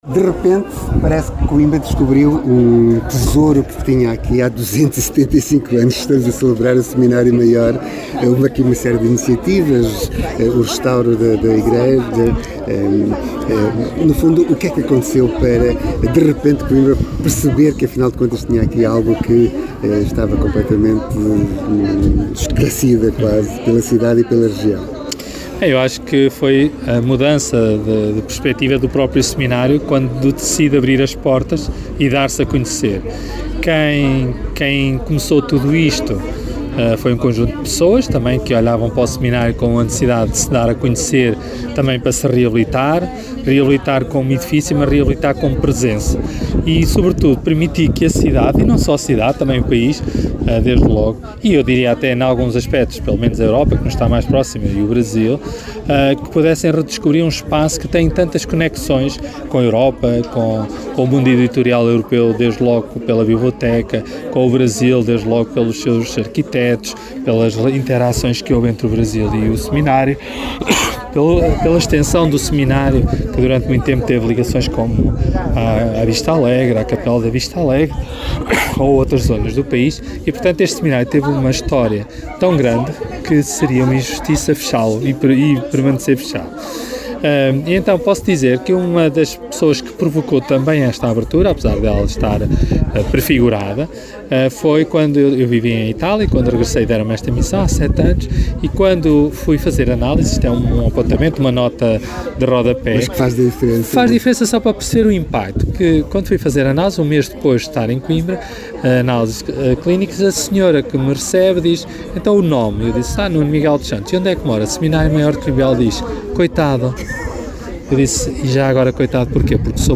Seminário Maior de Coimbra está a celebrar 275 anos. Uma breve conversa